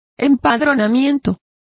Complete with pronunciation of the translation of census.